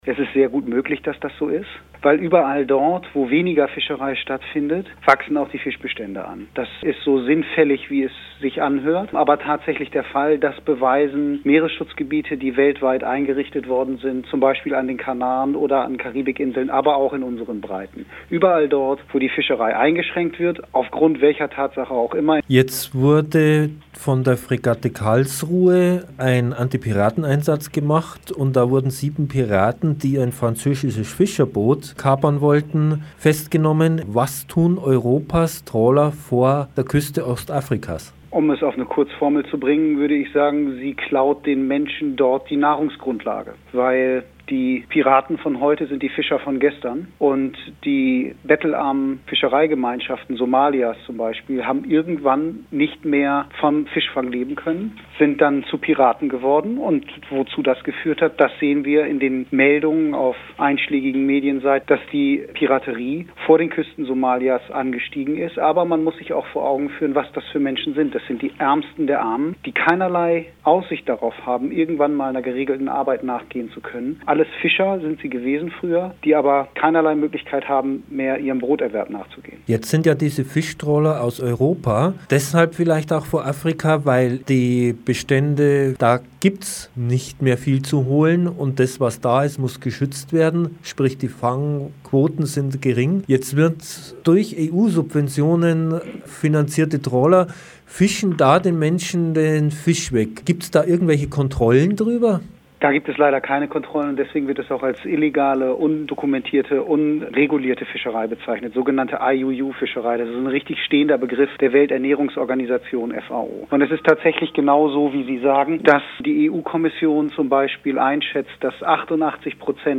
Ein Greenpeaceexperte für Meeresbiologie nimmt Stellung zu Presseverlautbarungen, daß sich die Fischbestände an der Ostküste Afrikas erholen.